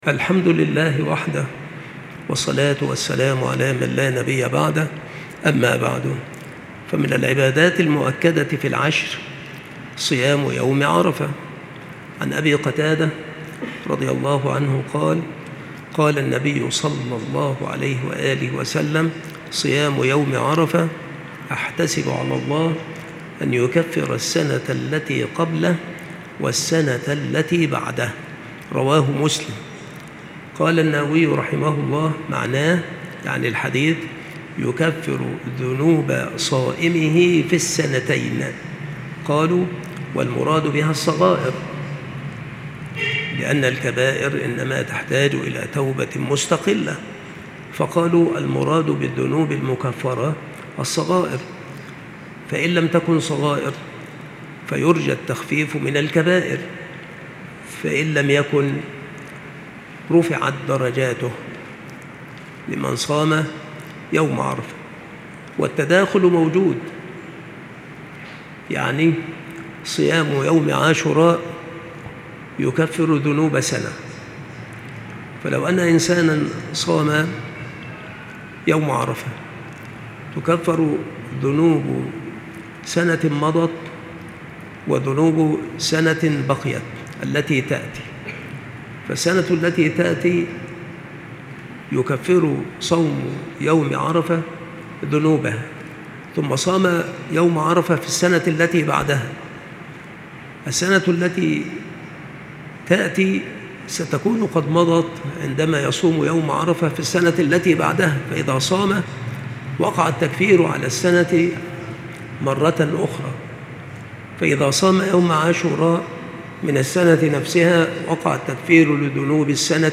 مكان إلقاء هذه المحاضرة بالمسجد الشرقي - سبك الأحد - أشمون - محافظة المنوفية - مصر